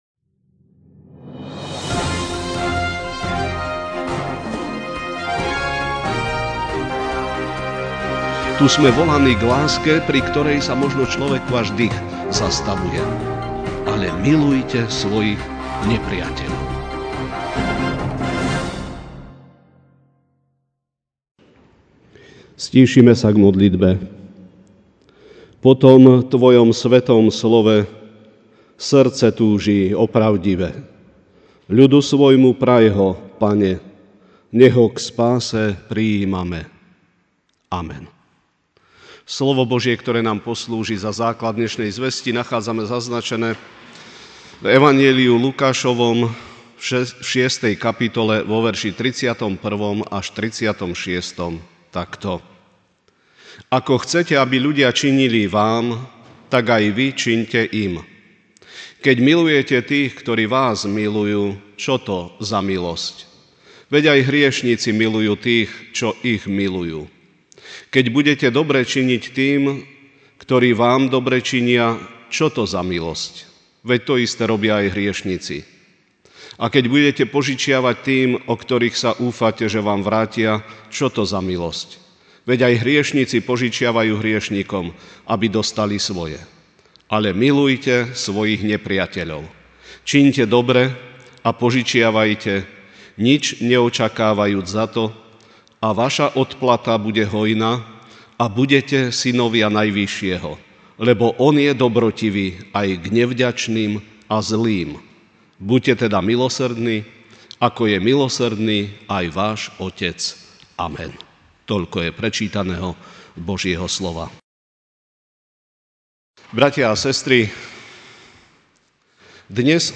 Večerná kázeň: Milosrdní (Lk 6, 31-36) Ako chcete, aby ľudia činili vám, tak aj vy čiňte im.